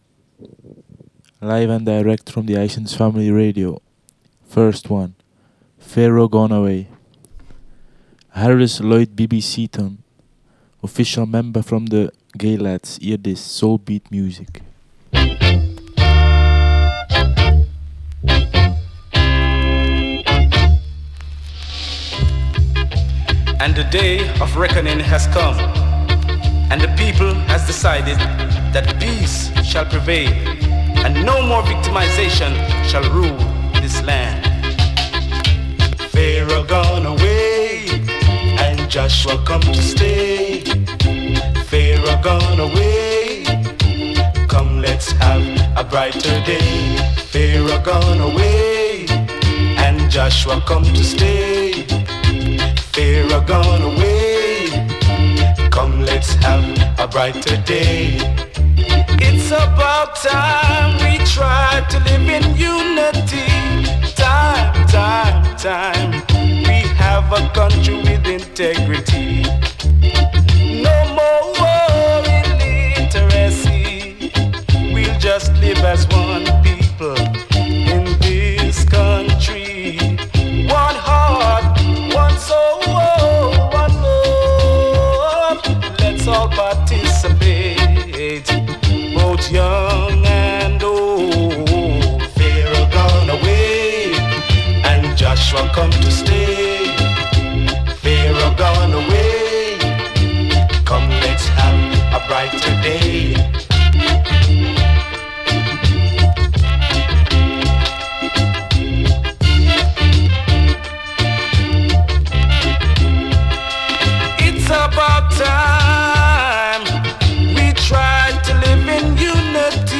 From early Roots to deep Dub !
Recorded straight from a 4-way Jored Preamp.